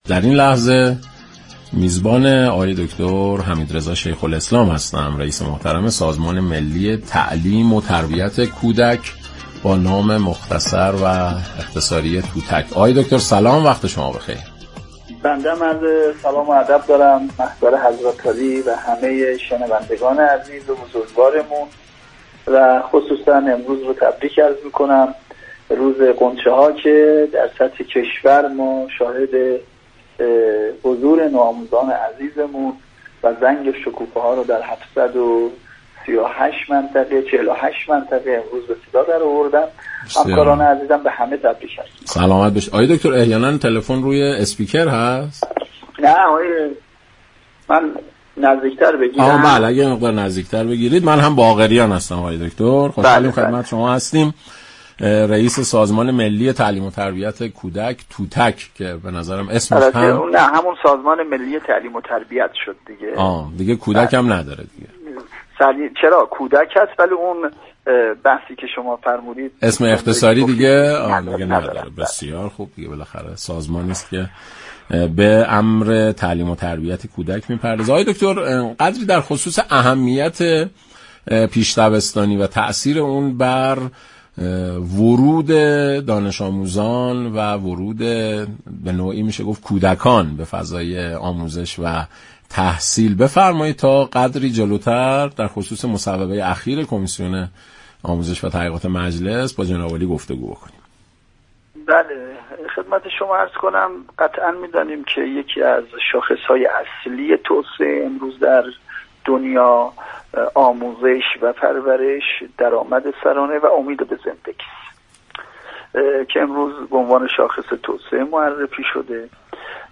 به گزارش فضای مجازی رادیو ایران، حمیدرضا شیخ‌الاسلام رییس سازمان ملی تعلیم‌و‌تربیت كودك در برنامه ایران امروز گفت:در دنیای امروز، آموزش و پرورش، درآمد سرانه و امید به زندگی یكی از شاخصه‌های اصلی توسعه است.